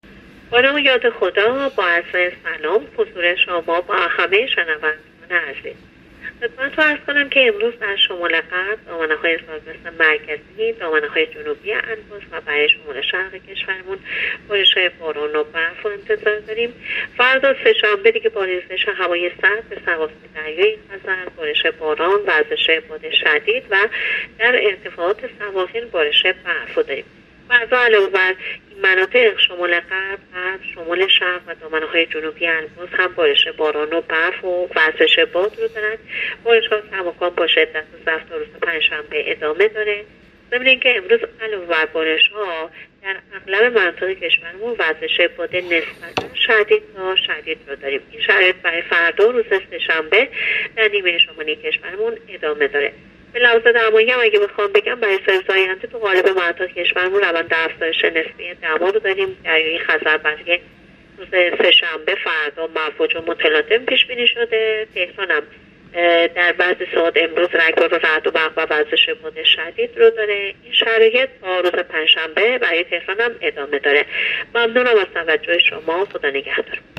گزارش رادیو اینترنتی از آخرین وضعیت آب و هوای بیستم بهمن؛